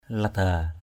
/la-d̪a:/ (cv.) lida l{d% (d.) gác = étagère, soupente. caik kaya di ngaok lada =cK ky% d} _z<K ld% để đồ ở trên gác.